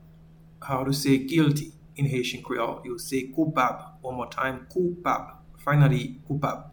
Pronunciation:
Guilty-in-Haitian-Creole-Koupab.mp3